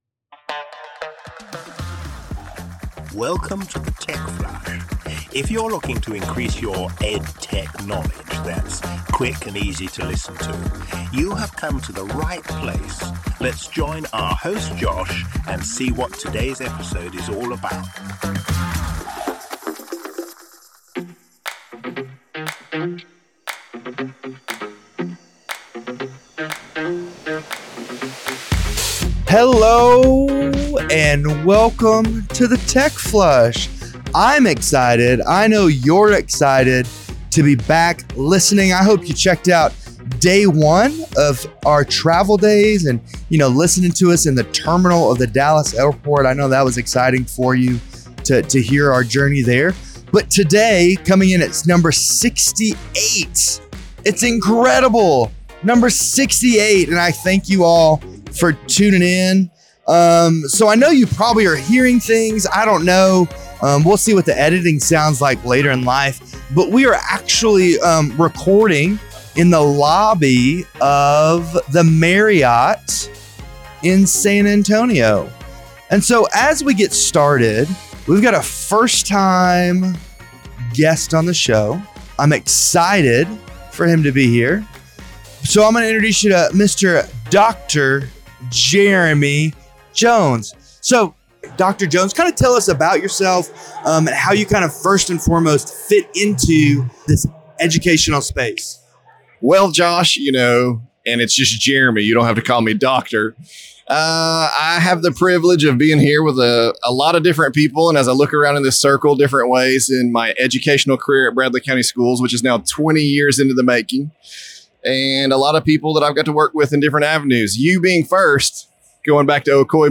The Tech Flash team is taking over the Marriott lobby in San Antonio for Day 1 of the TCEA Conference.